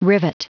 Prononciation du mot rivet en anglais (fichier audio)
Prononciation du mot : rivet